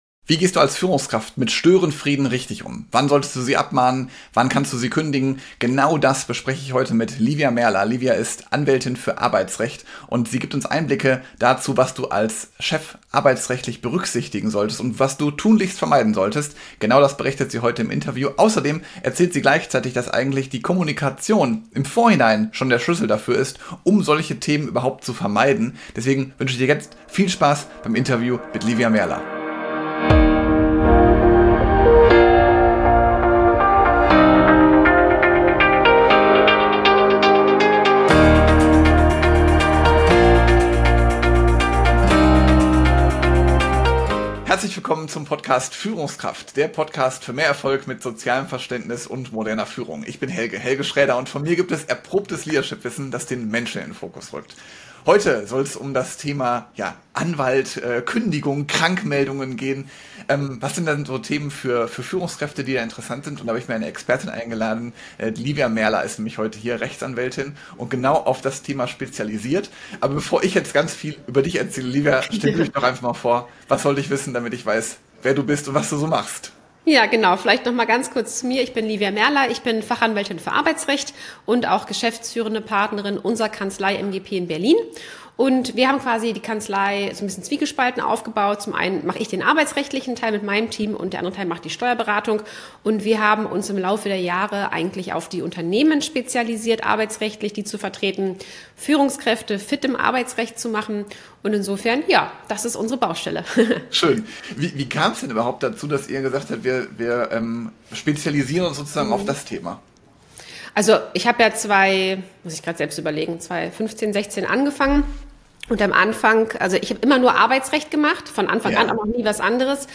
Nr. 304 - Abmahnen oder direkt kündigen? Störenfriede richtig führen - Interview